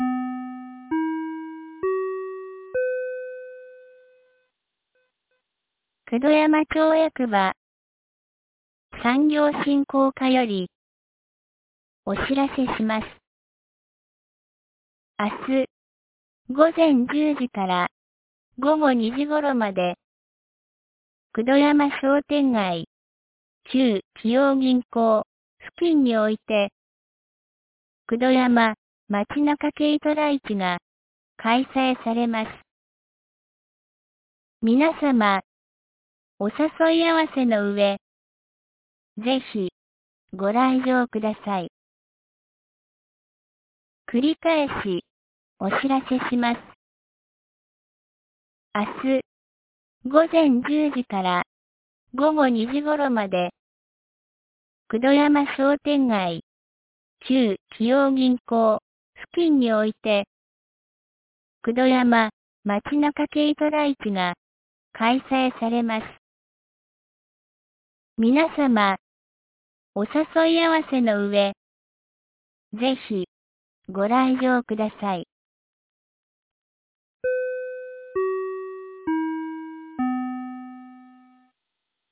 2025年04月26日 16時06分に、九度山町より全地区へ放送がありました。